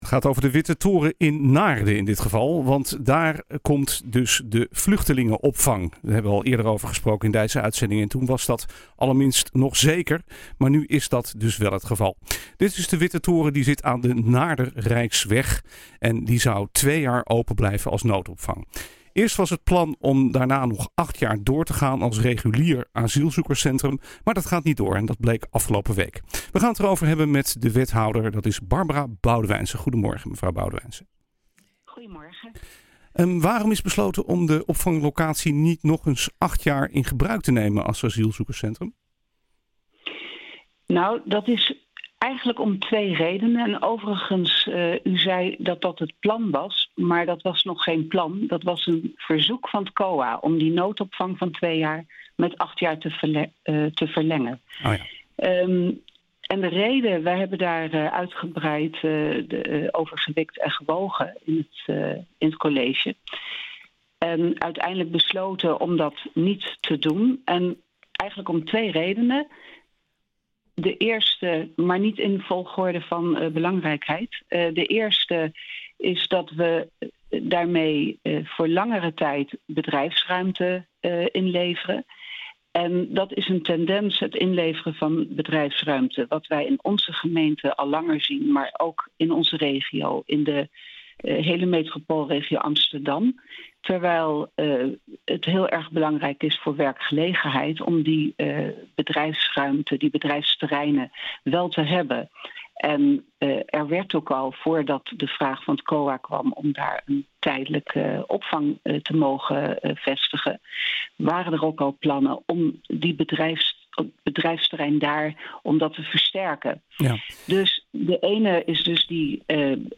Vluchtelingenopvang de Witte Toren, aan de Naarder Rijksweg, blijft maar twee jaar open als noodopvang. Eerst was het plan om daarna nog acht jaar door te gaan als regulier asielzoekerscentrum, maar dat gaat niet door, bleek afgelopen week. We gaan het erover hebben met wethouder Barbara Boudewijnse.